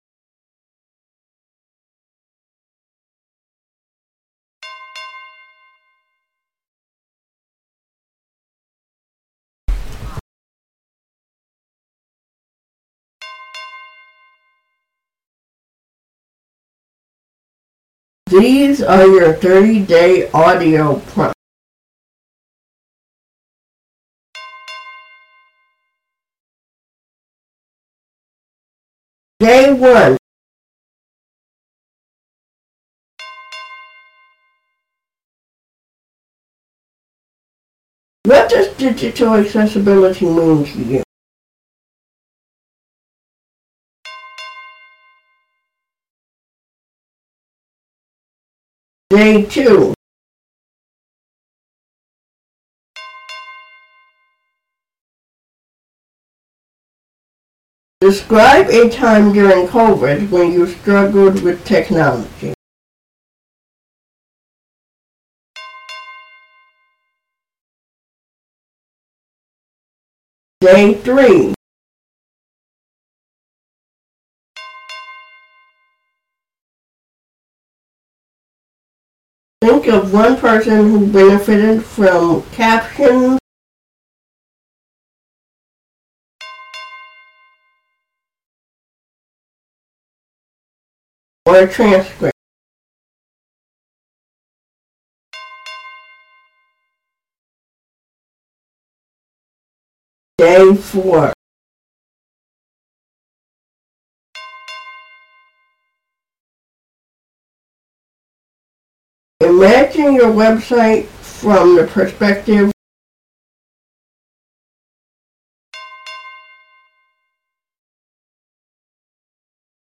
A Blue Green Galaxy: Accommodation Accessibility Journal A 30-prompt audio journal designed for print-disabled reflection—one continuous file, with gentle pause bells to guide your pacing.…